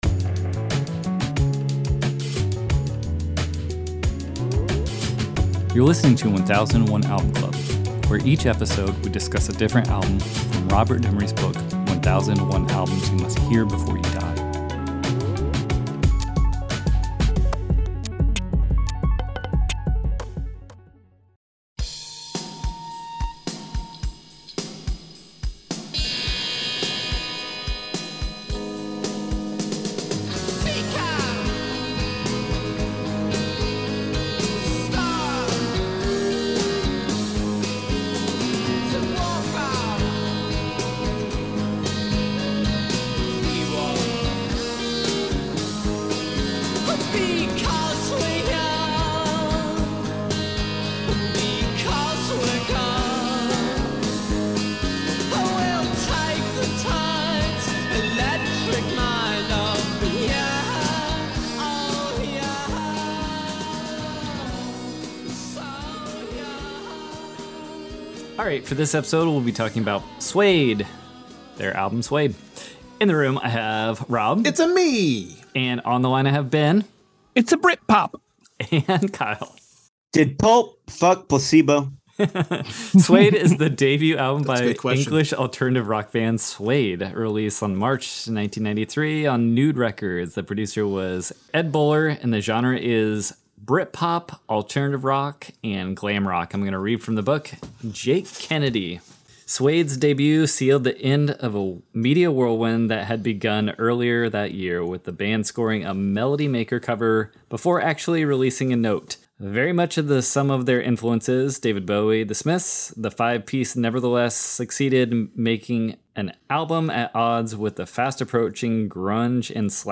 Friends make a pact to listen and discuss 1001 of the best albums in a basement tiki bar